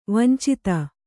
♪ vancita